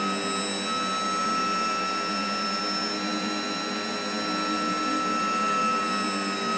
flaps-motor.wav